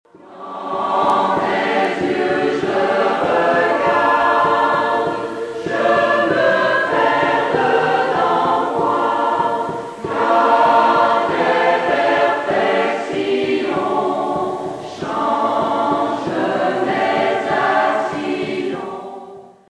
75 hommes et femmes originaires de la côte Nord (de Tadoussac à Forestville) ont choisi de se rencontrer régulièrement pour fusionner leurs voix dans un choeur vibrant. La chorale baptisée "Les gens de mon pays" avec les encouragements de Gilles Vigneault, était, dès la 1ère année de son existence, en mesure de partager avec le public le bonheur de chanter Plamondon, Vigneault et des mélodies du moyen âge.
chorale.mp3